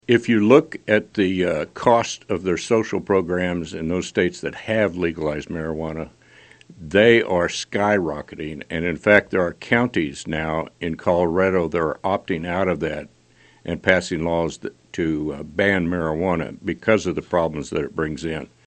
51st District Representative Ron Highland (R-Wamego), a guest on KMAN’s In Focus recently, says after lawmakers passed a bill last year authorizing hemp research within federal regulations, the legislature may have to come back to the bill and potentially repeal it altogether. Highland says it could open up new income avenues for ag producers, but stopped short of saying that legal marijuana is a possibility in Kansas.